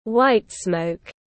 Màu trắng khói tiếng anh gọi là whitesmoke, phiên âm tiếng anh đọc là /waɪt sməʊk/.